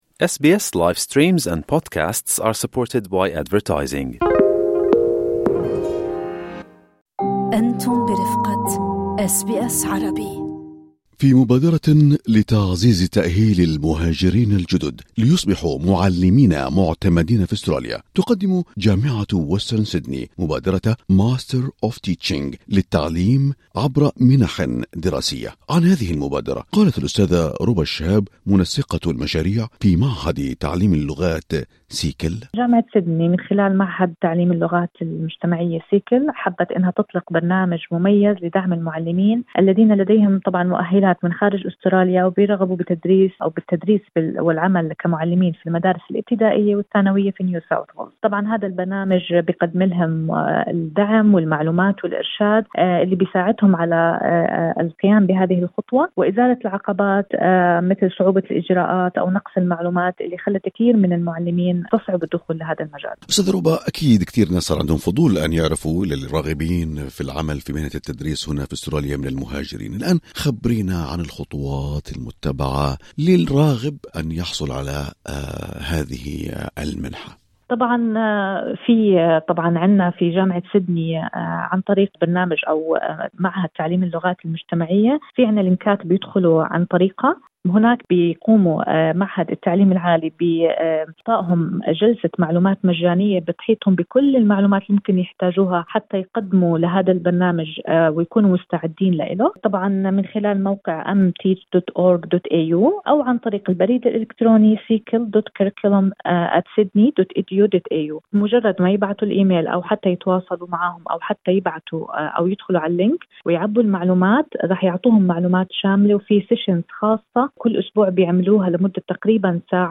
في حديثها مع إذاعة اس بي أس عربي